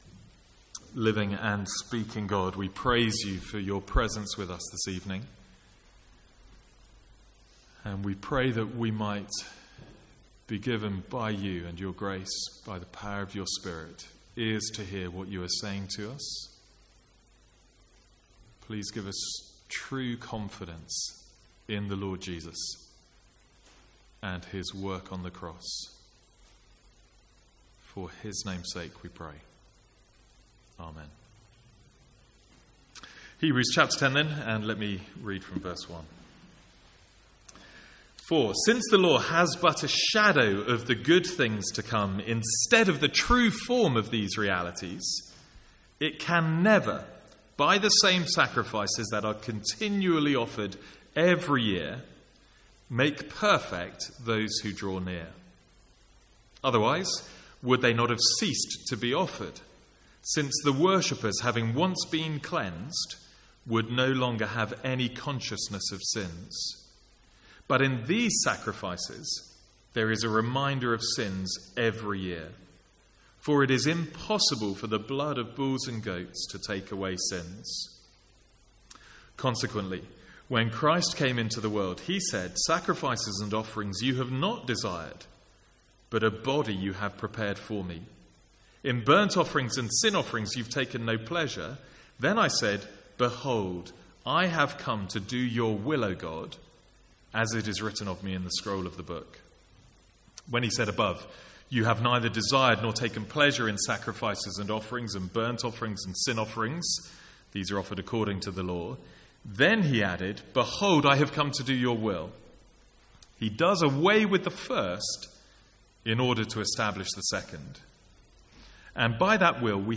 Sermons | St Andrews Free Church
From the Sunday evening series in Hebrews, on Easter Sunday 2016.